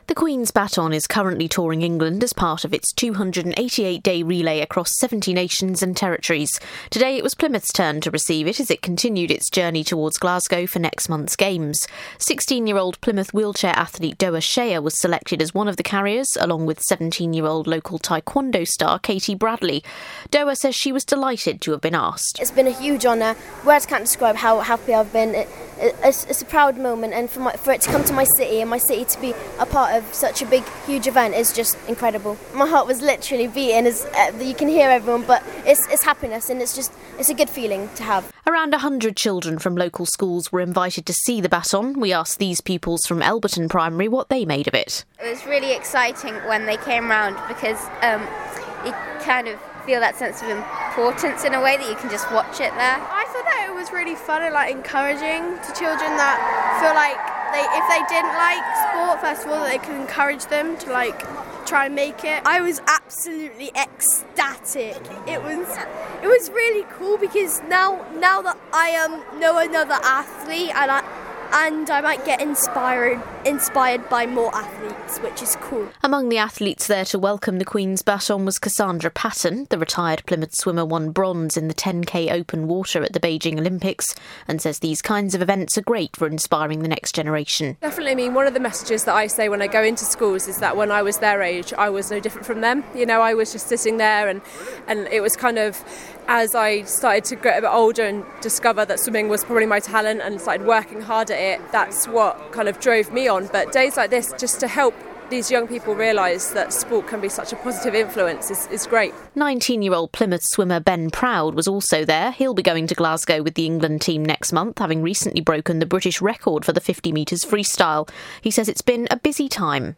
There were excited scenes today as the Commonwealth Baton visited Plymouth.